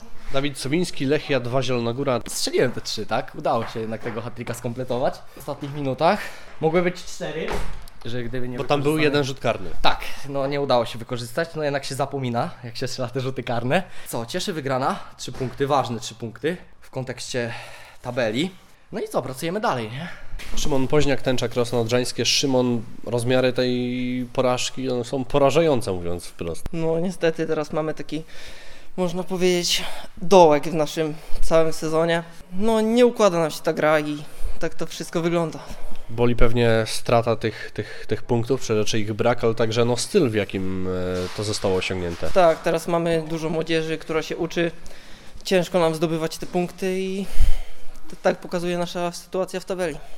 Mecz oceniają zawodnicy obu drużyn: